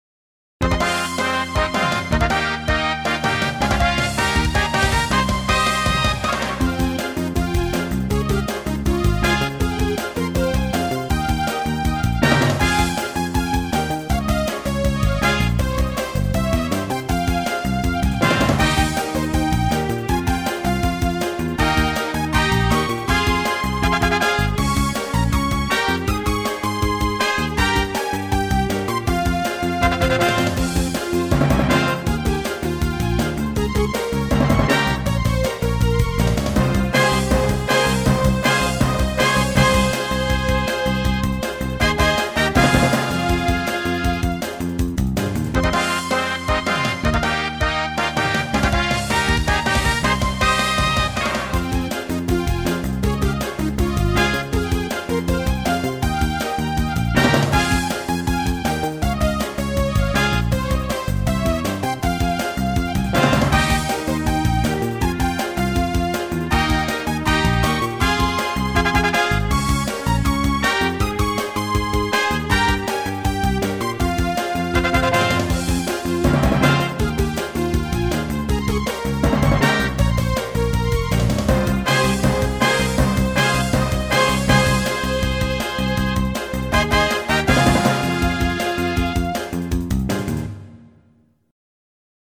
※音源はＳＣ８８Pro。